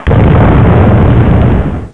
WYBUCH3.mp3